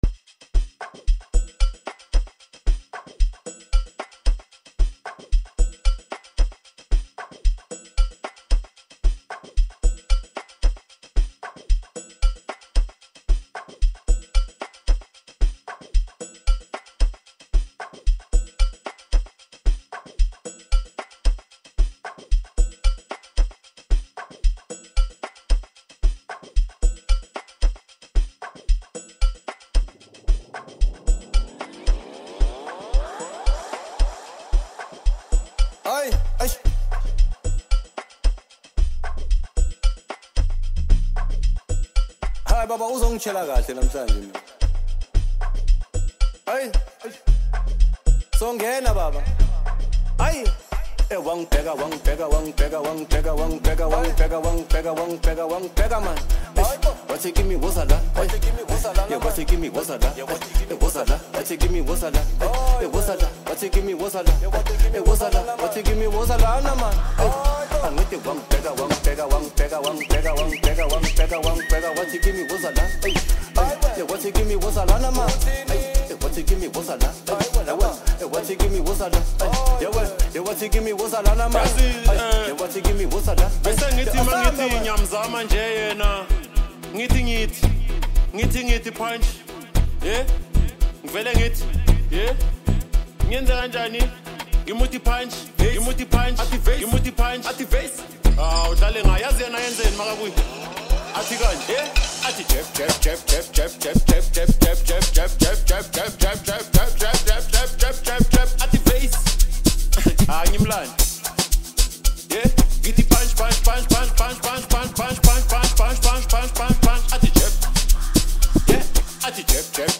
January 5, 2026 admin Amapiano 0